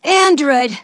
synthetic-wakewords
ovos-tts-plugin-deepponies_Spike_en.wav